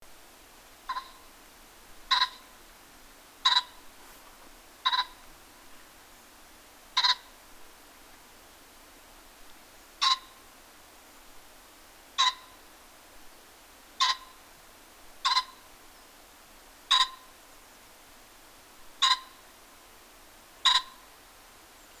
На этой странице собраны разнообразные звуки фазанов – от характерного квохтания до резких тревожных криков.
Продолжительный звук фазана